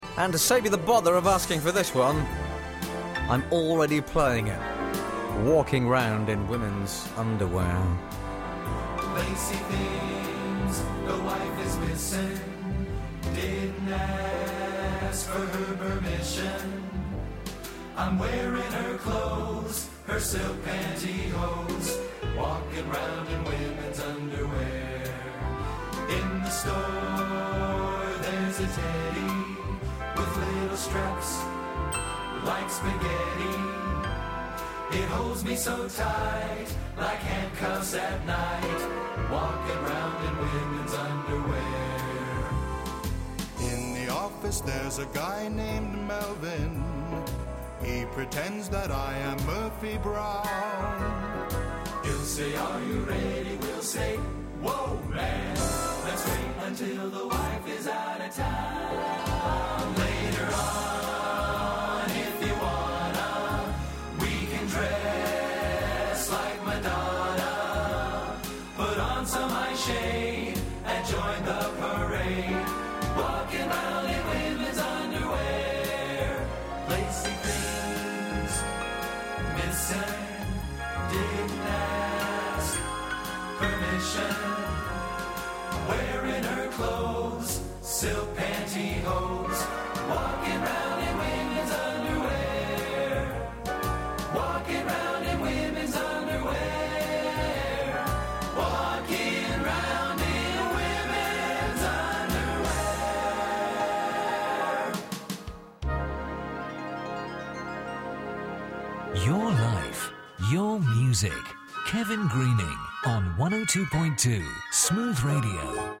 Christmas Eve 2007 MP3 track .. 1.77Mb.   The DJ's voice you will hear is that of the late Kevin Greening.